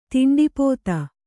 ♪ tinḍi pōta